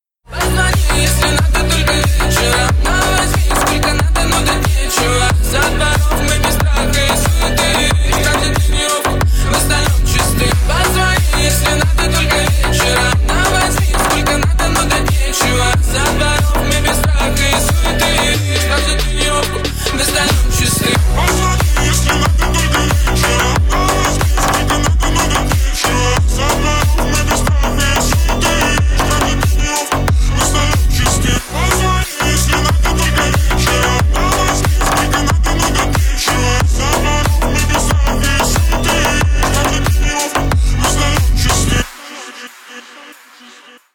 Рингтоны на звонок
Нарезка припева на вызов